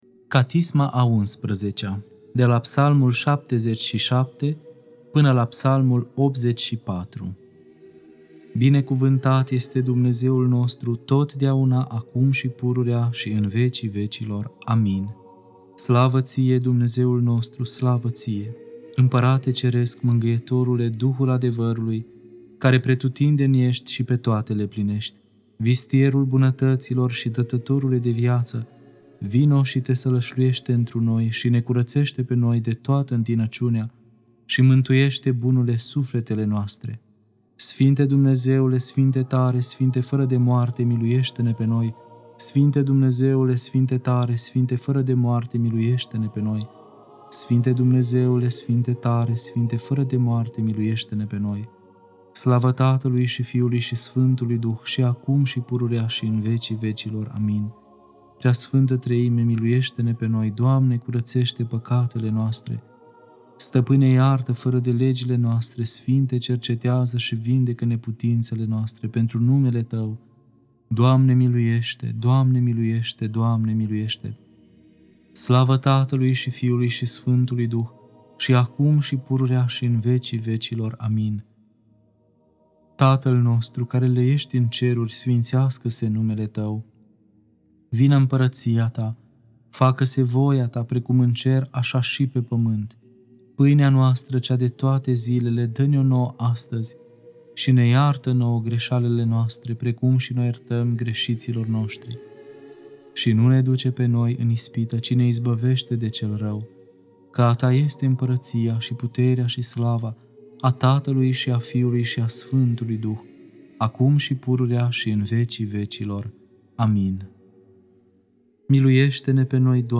Catisma a XI-a (Psalmii 77-84) Lectura